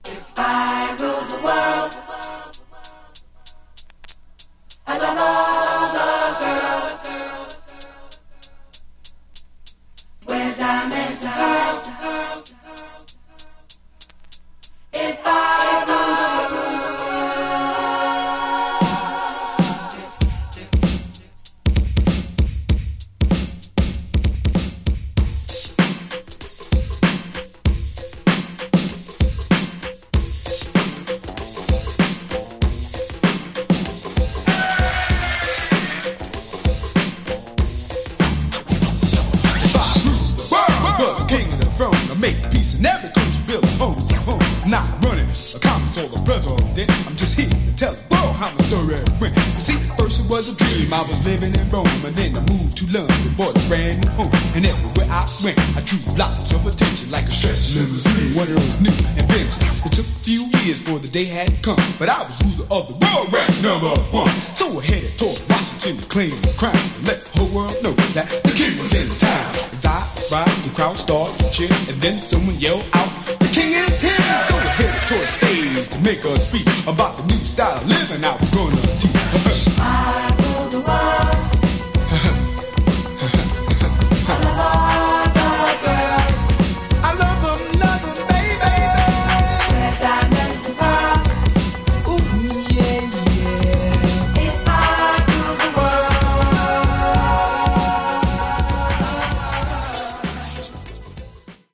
The audio is pristine Mint!